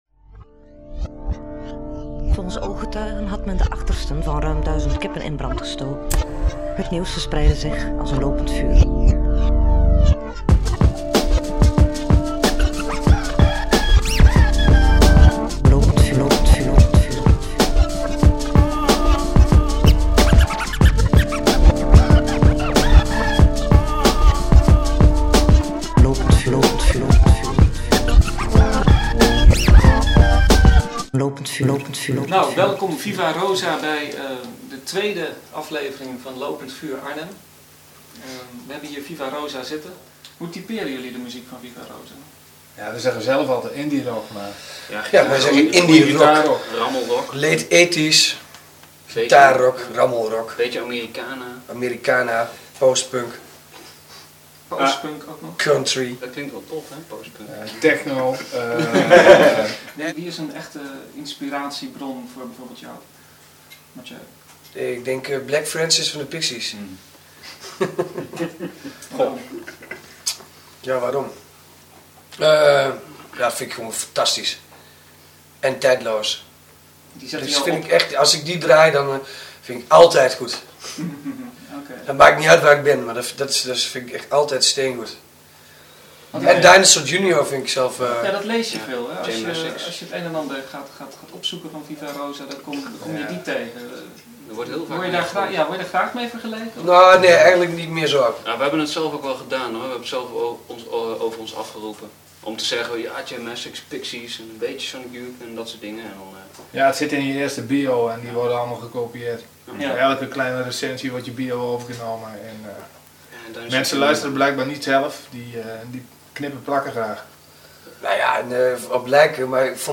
inclusief mondharmonica a la Neil Young.